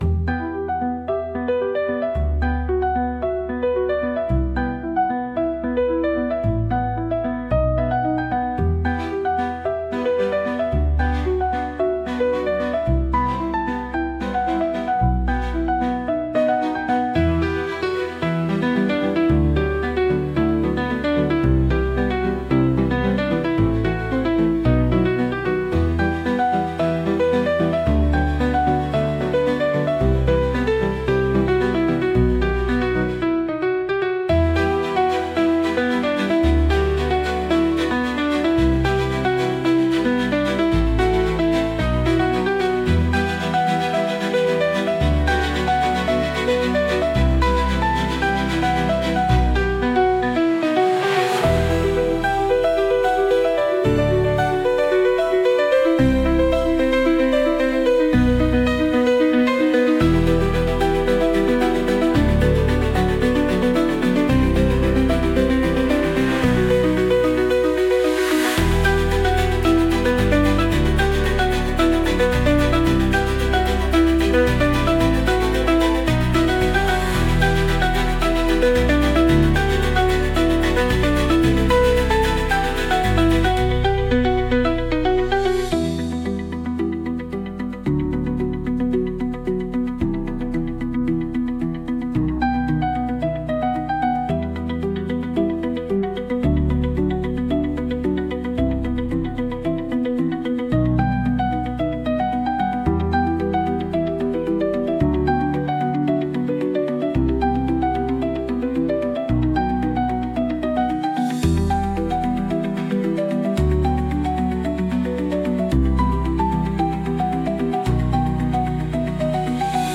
Instrumental / 歌なし
一度聴いたら忘れられない、弾むようなピアノの旋律が印象的な一曲。
柔らかなストリングスが優しく包み込みますが、その奥には決して折れない「芯の強さ」を感じさせます。
しっとりとした雰囲気の中に凛とした強さが同居する、まさにタイトル通りの世界観。